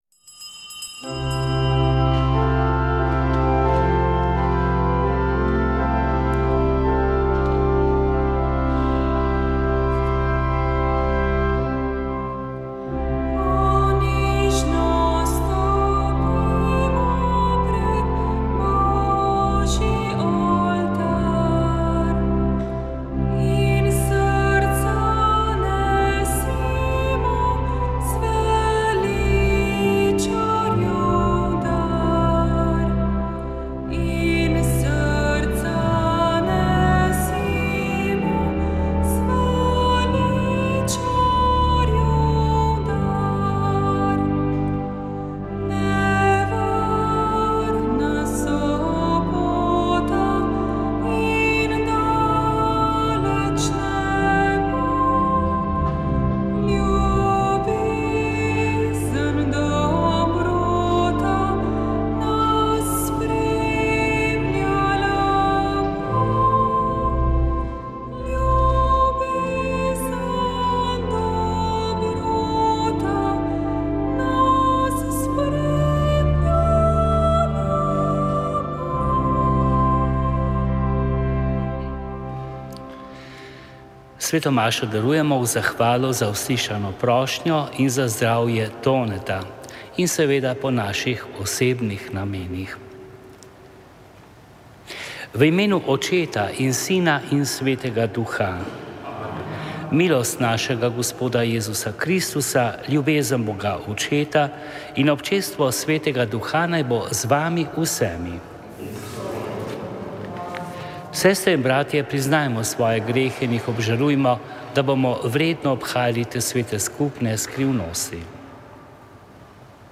Sveta maša
Prenos konventne maše iz fračiškanske cerkve Marijinega oznanjenja v Ljubljani
Konventna maša iz frančiškanske cerkve Marijinega oznanjenja v Ljubljani in molitev za zdravje sester Uršulink.